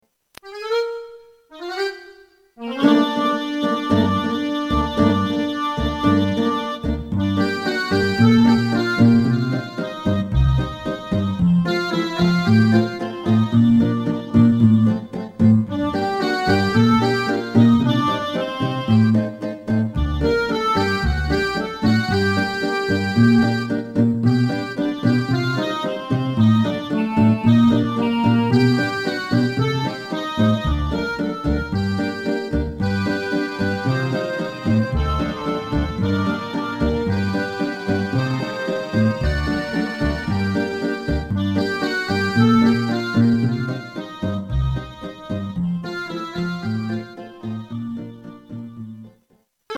Помедленее.